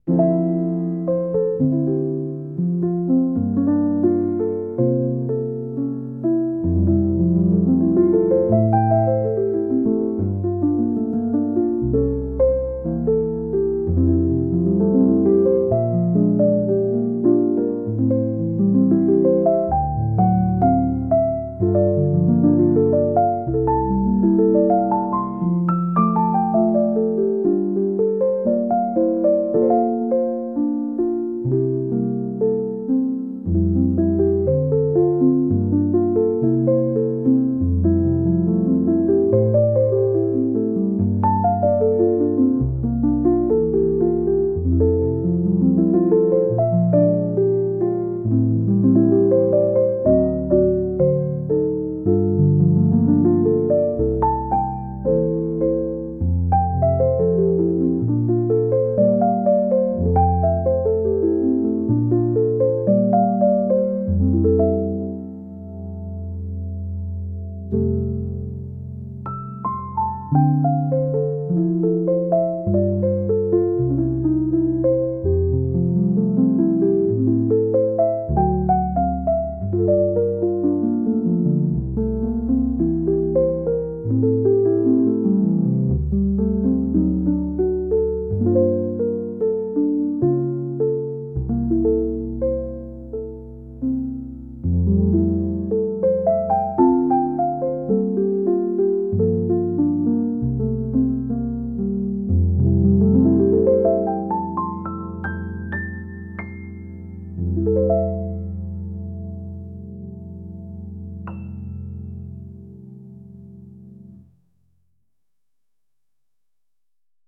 ジャンルJAZZ
曲調Bar, Chill, Lo-Fi, ゆったり, カフェ, , 日常,